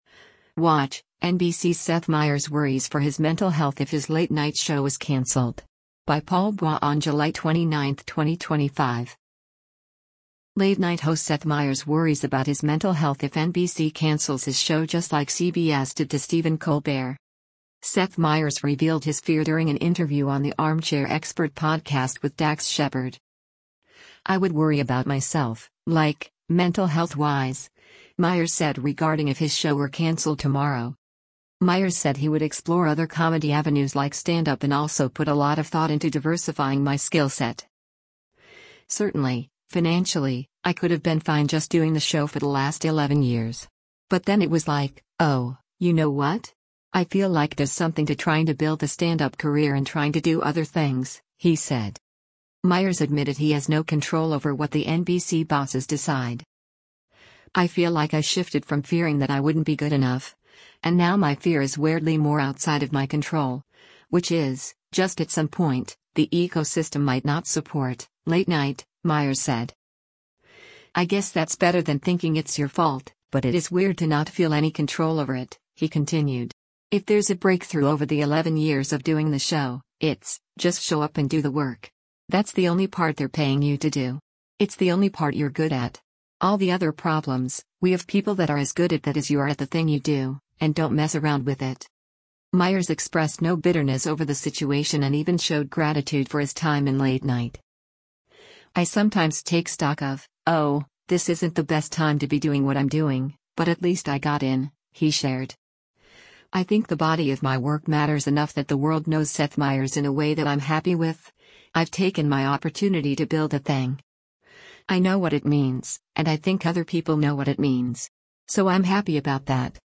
Seth Meyers revealed his fear during an interview on The Armchair Expert podcast with Dax Shepard.